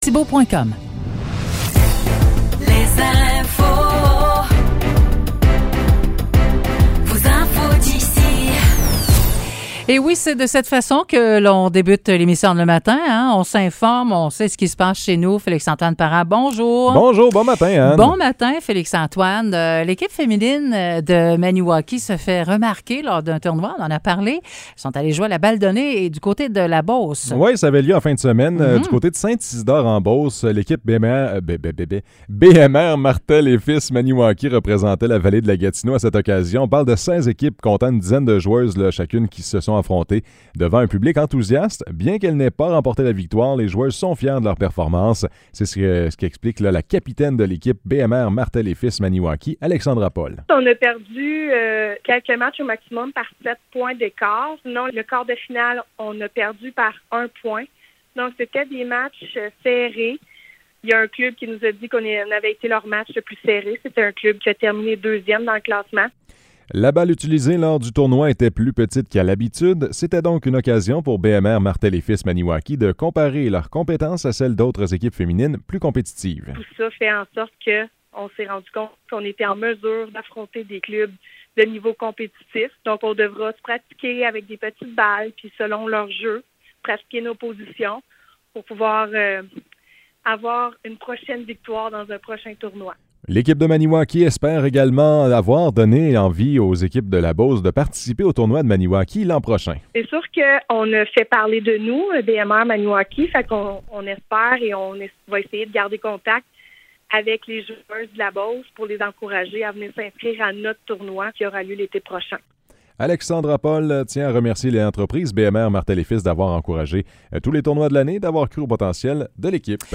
Nouvelles locales - 19 septembre 2023 - 9 h